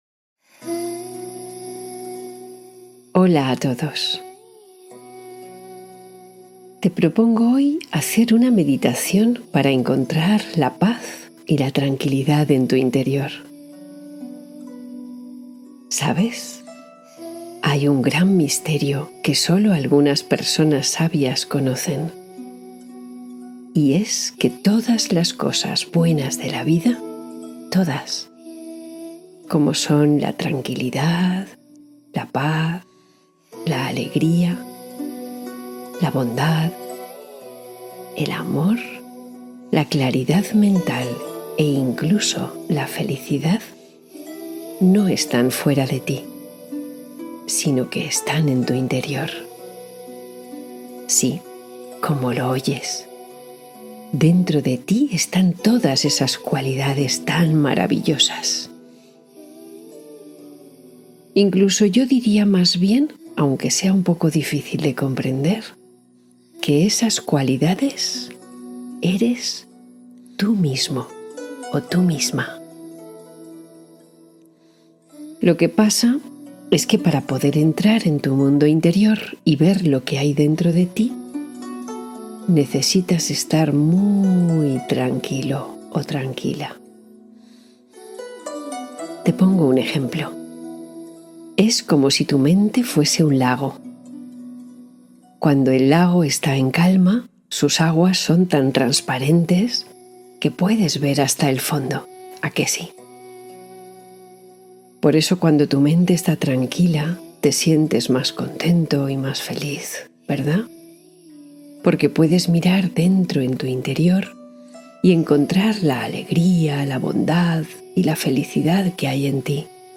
Tranquilidad antes de dormir: meditación suave para niños